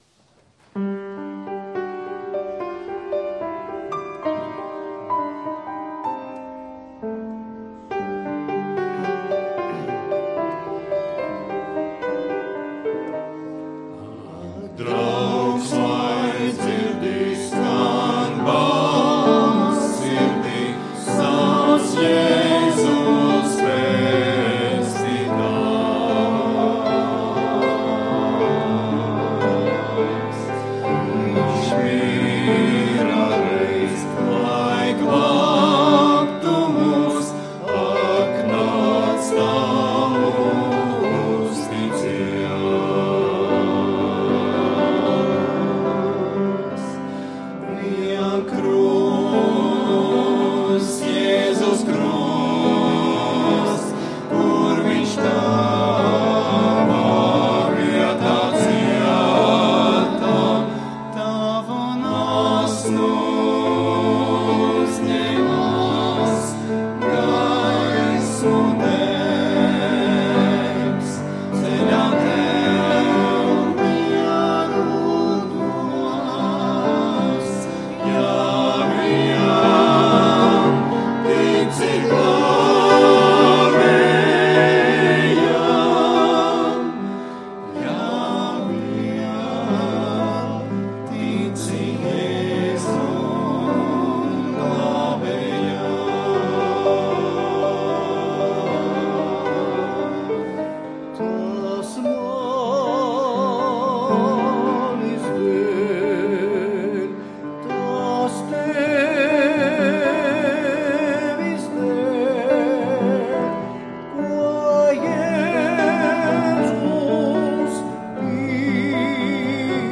Svētrunas